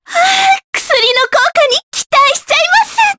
Mediana-Vox_Skill6_jp.wav